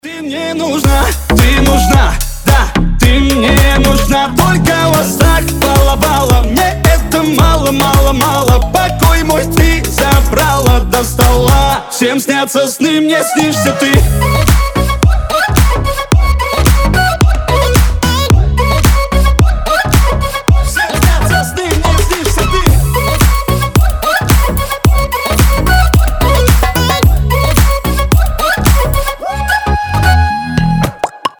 • Качество: 320, Stereo
поп
ритмичные
восточные мотивы
зажигательные
веселые
заводные